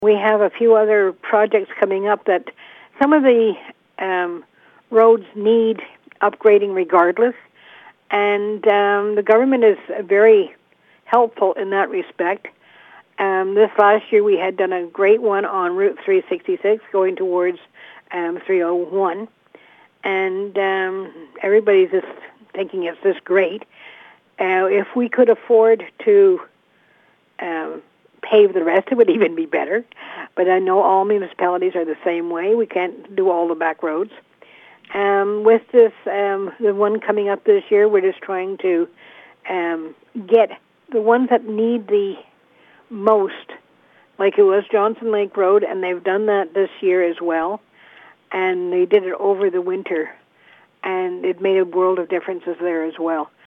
Thorne Mayor Karen Kelly discussed the 2022 budget with CHIP 101.9. Photo courtesy of MRC Pontiac.